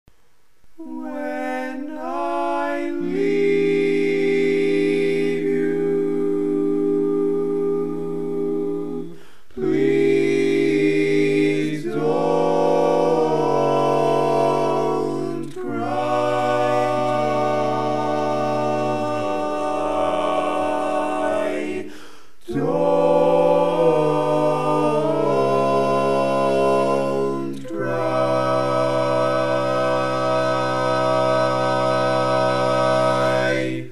Key written in: C Minor
How many parts: 4
Type: Barbershop